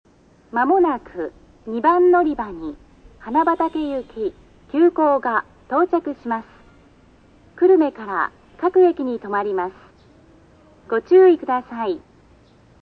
●スピーカー：ソノコラム・小
●音質：D
２番のりば 接近放送 急行・花畑　（62KB/12秒）
全ホーム同じ声で統一されていますが、ちょっとイントネーションがおかしいような......。